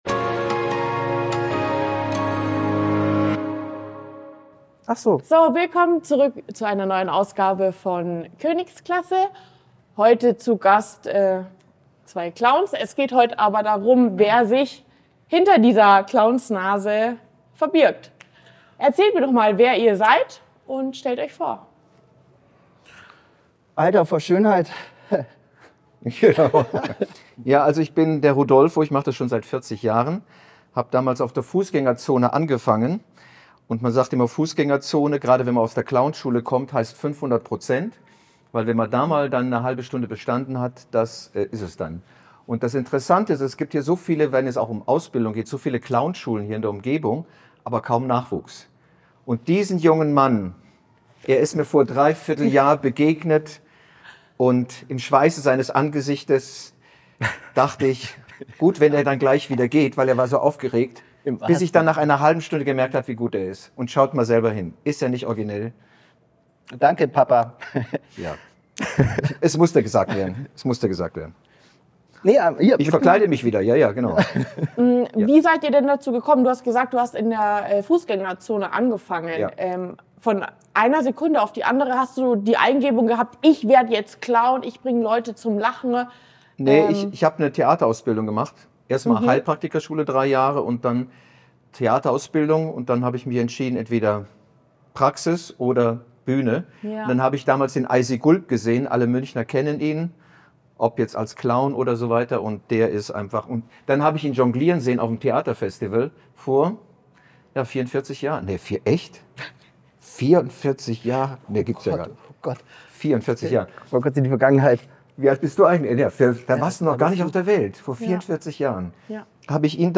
Beschreibung vor 1 Jahr Zusammenfassung In diesem Interview sprechen zwei professionelle Clowns